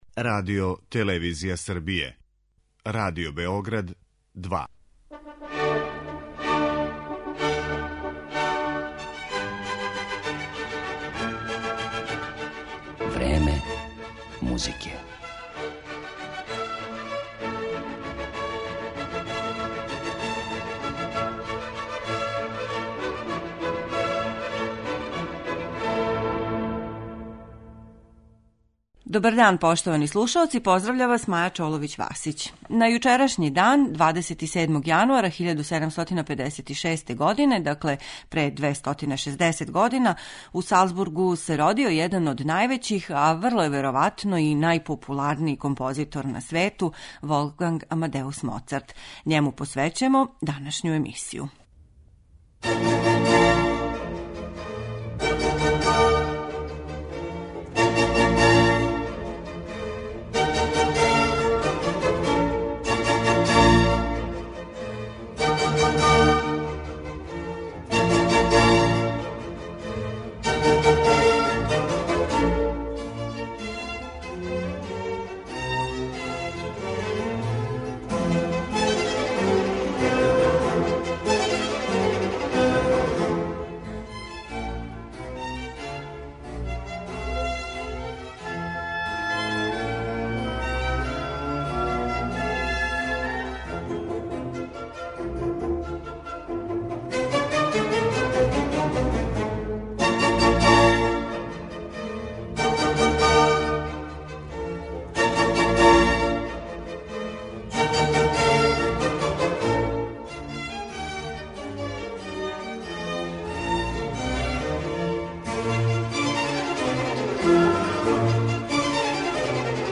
Више детаља о овом открићу, као и фрагменте сифмонија, међу којима су бр. 24, 25, 27 и 29, слушаћете у извођењу угледних европских ансамбала и диригената.